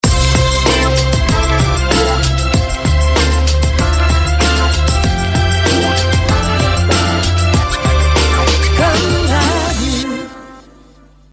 Indicatiu de la ràdio